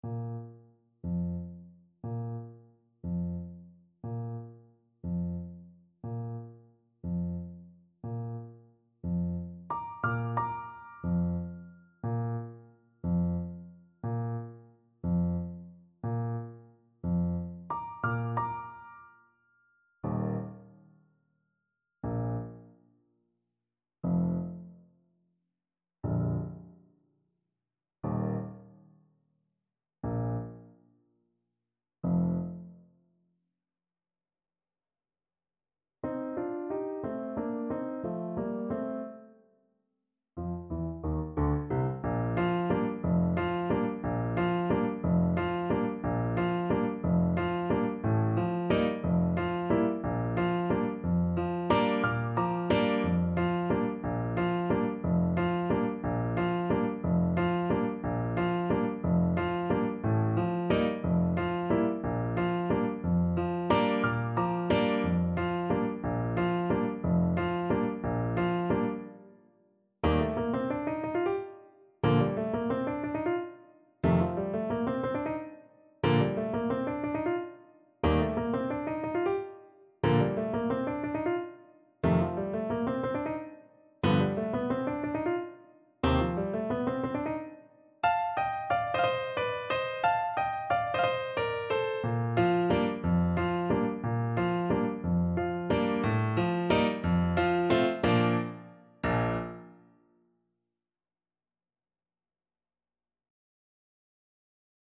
6/8 (View more 6/8 Music)
Classical (View more Classical Saxophone Music)